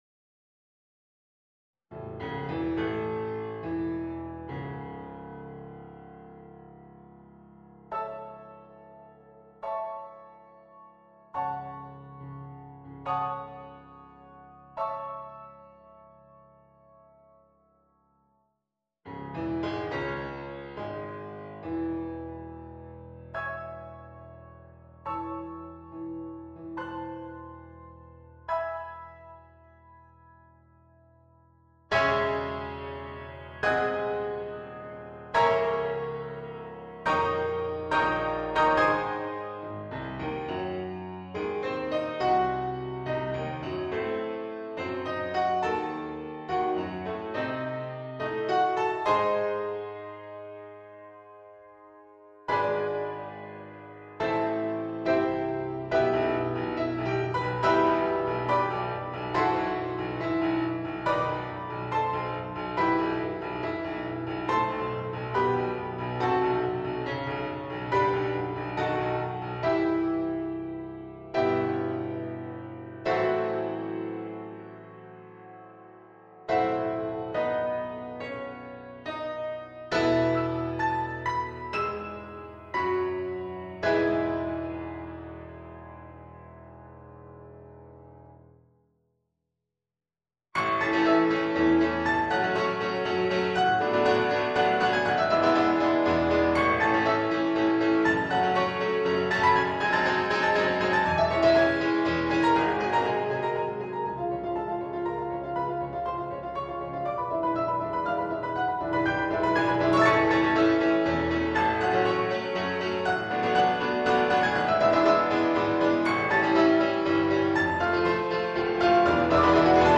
for piano duet
Piano - 2 players on 1 piano